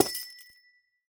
Amethyst break4.ogg.mp3
Amethyst_break4.ogg.mp3